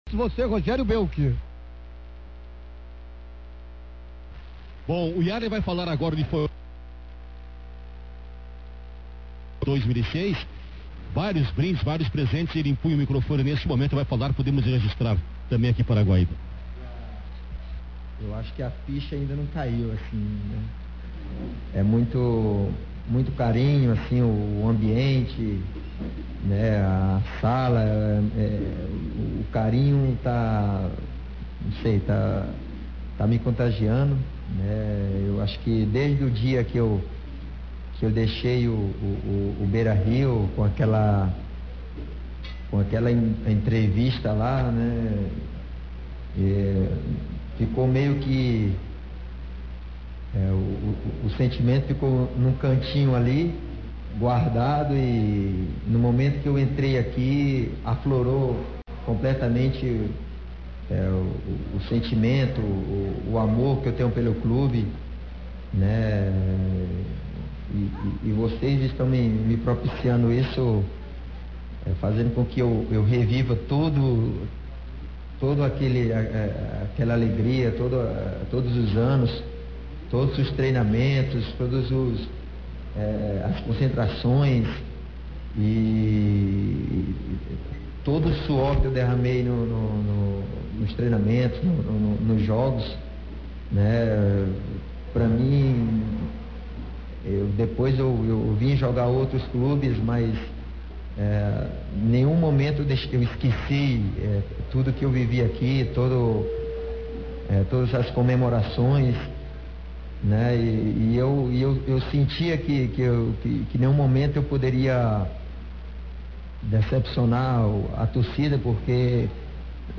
Transmissão Rádio Guaíba Homenagem Iarley – parte 05